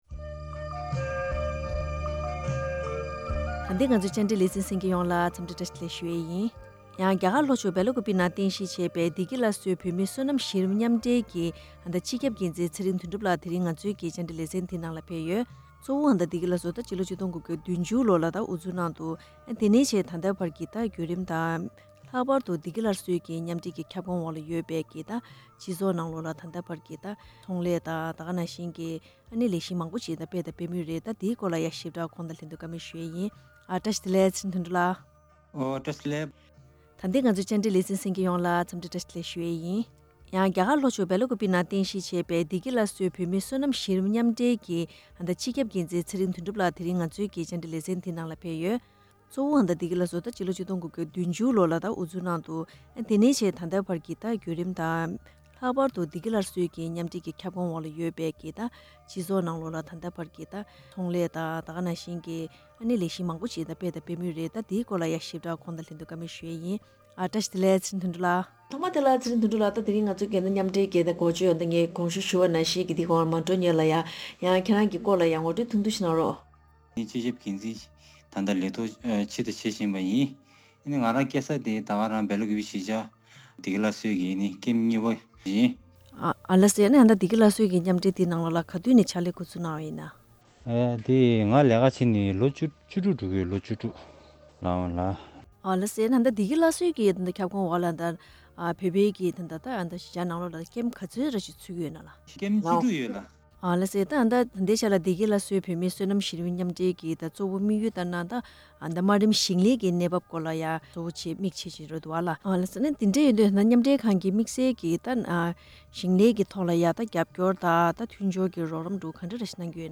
བཀའ་དྲི་ཞུས་པ་ཞིག་གསན་གནང་གི་རེད།།